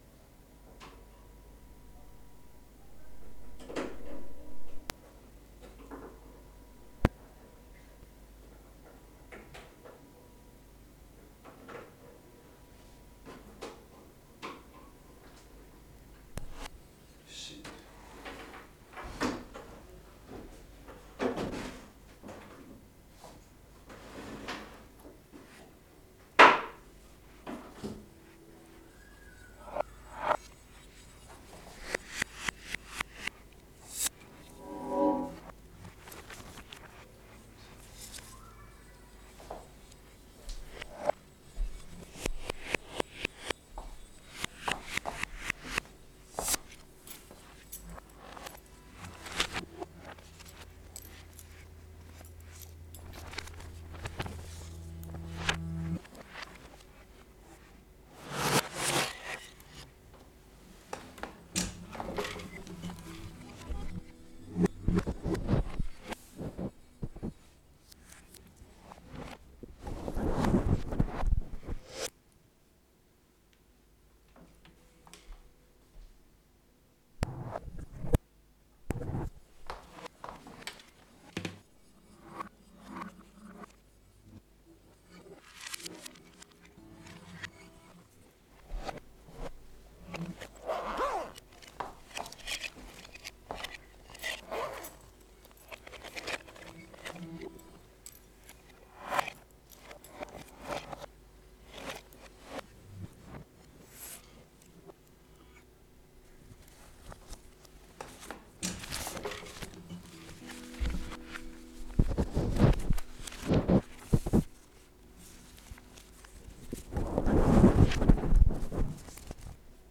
2015 Leerlaufcuts (Studio-Aufnahmen Leerstellen)
2015-08-04 at home (Tape) leerlaufcut.flac (4:13)